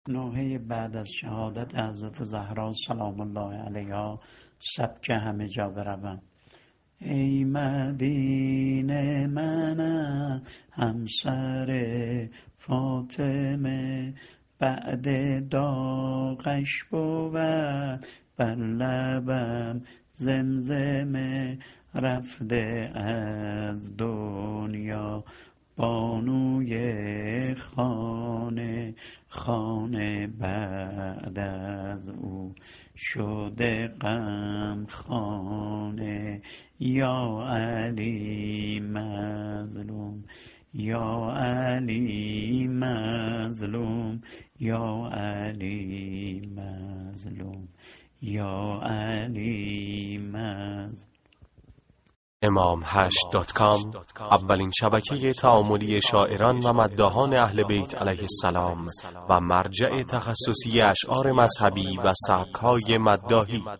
متن شعر و نوحه بعد از شهادت حضرت زهرا(س) -(ای مدینه منم همسرفاطمه)